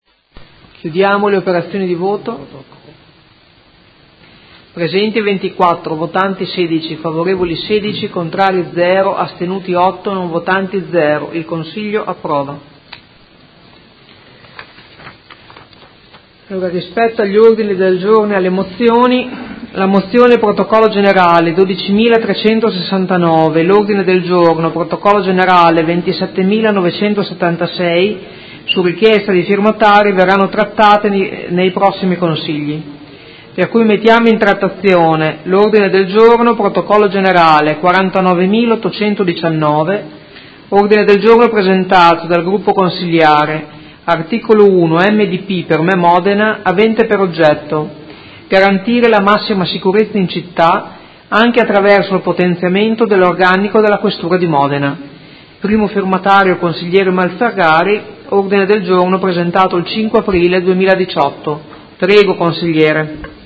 Seduta del 12/04/2018 Mette ai voti l'odg 53646. Approvato.